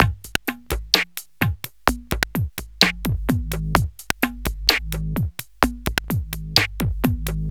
Downtempo 09.wav